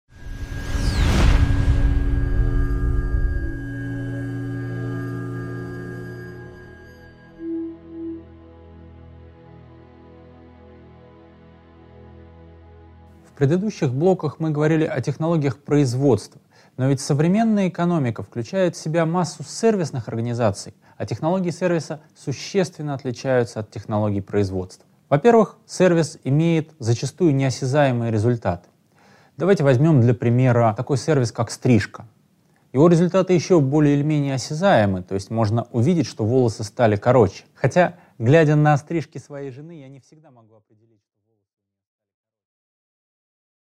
Аудиокнига 5.4. Технология сервиса | Библиотека аудиокниг